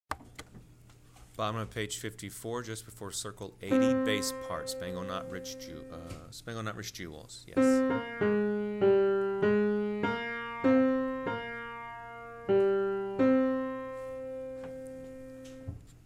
Bass parts-starting at circle 51 and before circle 80
10 80 bass part
10-80-bass-part.wma